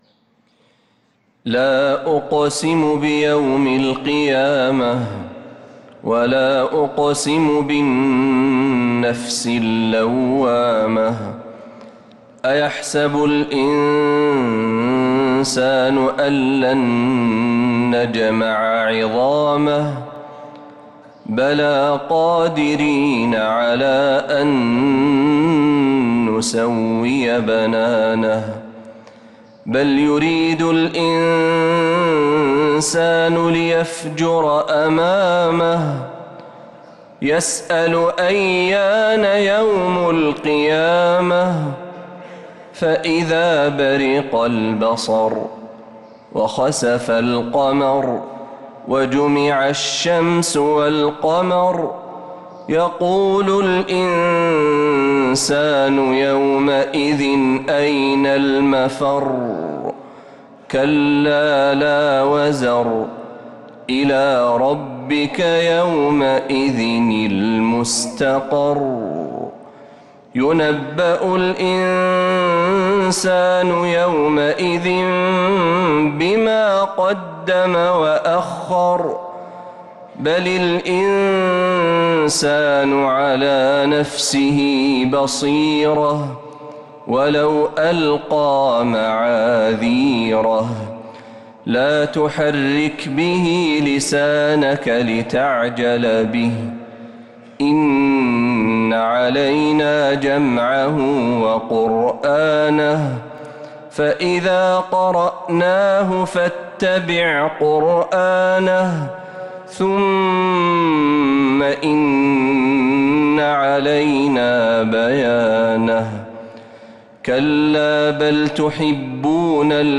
سورة القيامة كاملة من مغربيات الحرم النبوي للشيخ محمد برهجي | جمادى الأولى 1446هـ > السور المكتملة للشيخ محمد برهجي من الحرم النبوي 🕌 > السور المكتملة 🕌 > المزيد - تلاوات الحرمين